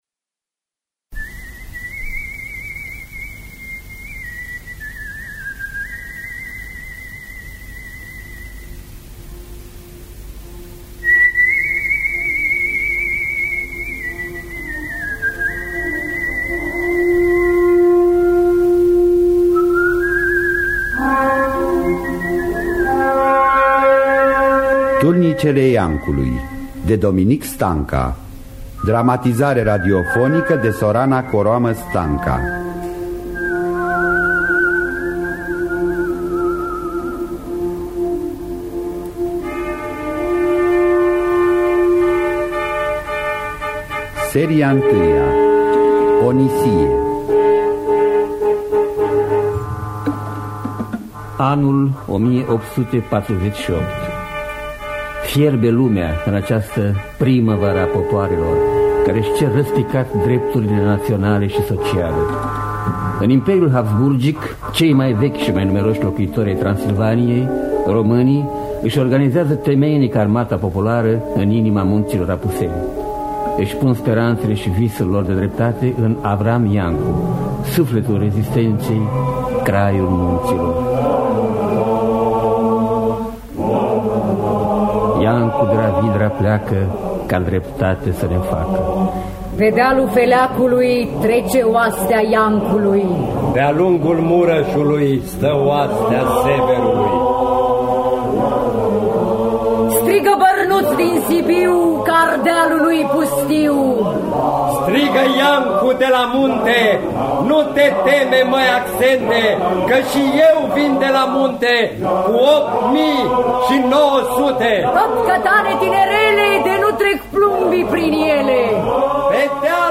Dramatizarea radiofonică de Sorana Coroamă-Stanca.